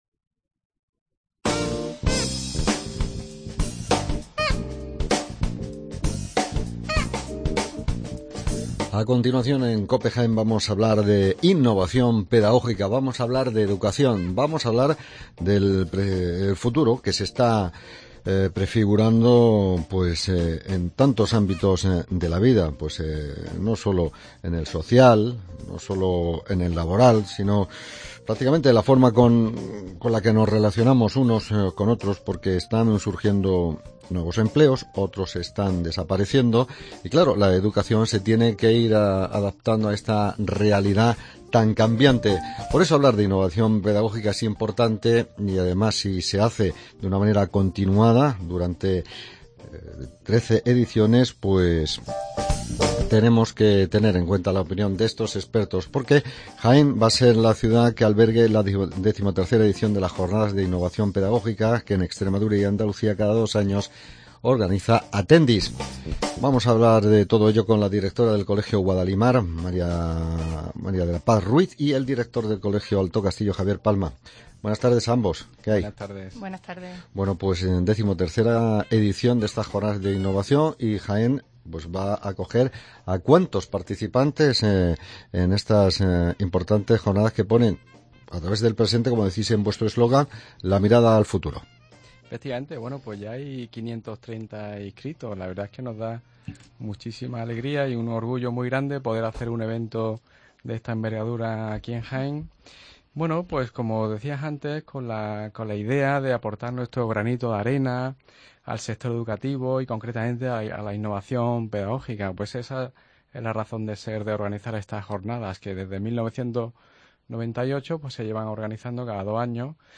Entrevista Attendis